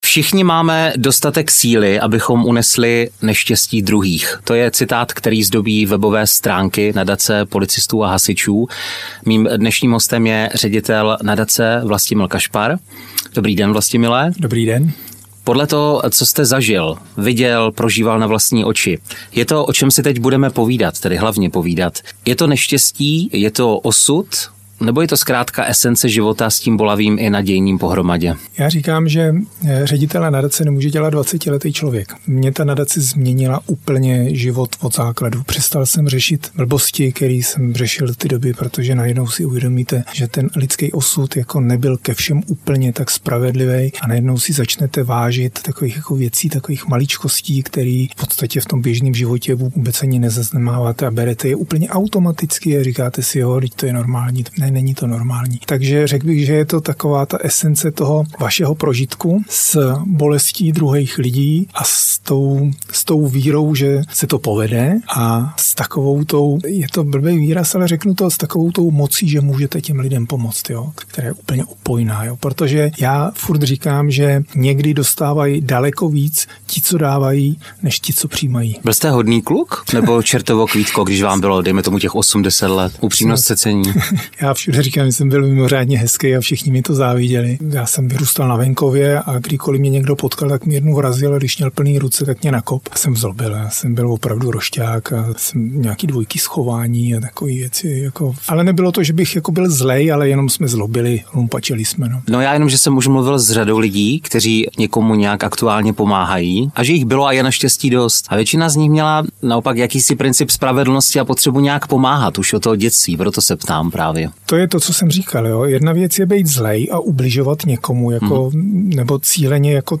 V této epizodě se ponoříte do zákulisí nadace, která mění osudy těch, co sami pomáhali. Poslechněte si inspirativní rozhovor o naději, síle a empatii, která hýbe světem.